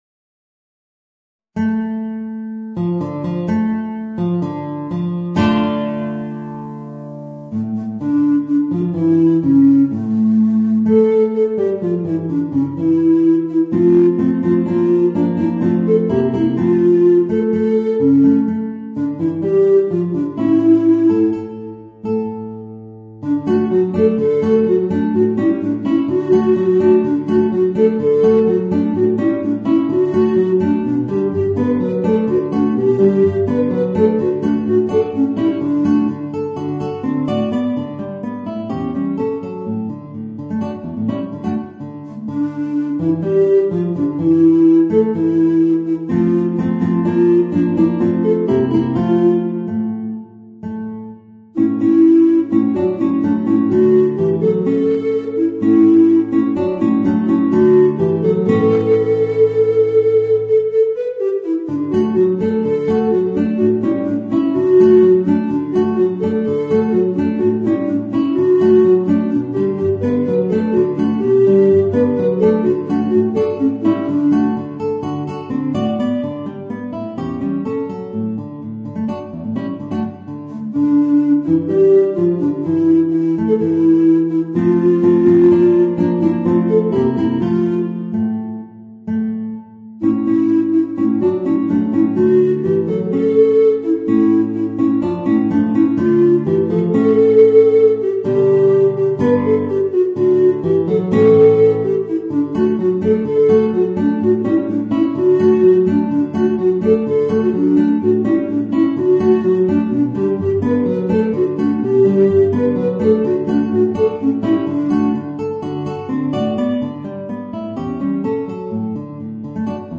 Voicing: Guitar and Bass Recorder